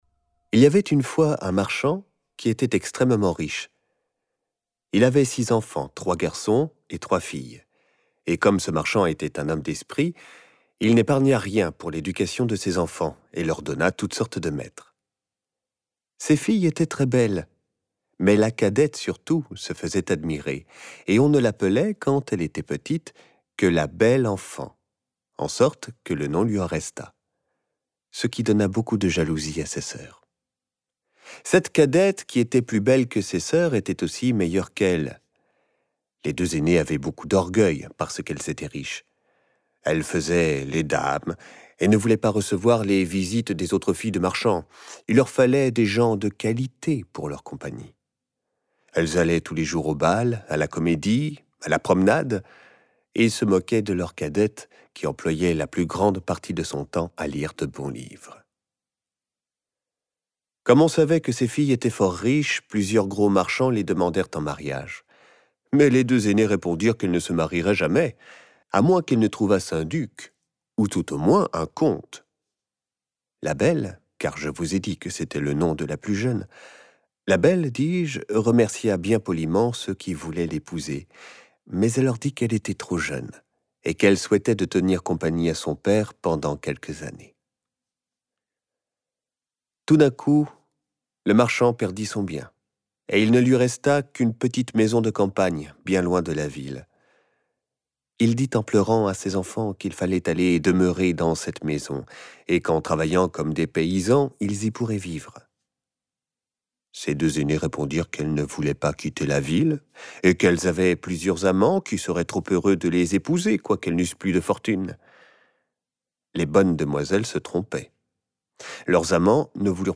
La production de ce livre-audio a été réalisée avec le concours de la classe de 5ème2 du Collège Picasso à Saint-Etienne-du-Rouvray (Seine-Maritime), en Mai 2018.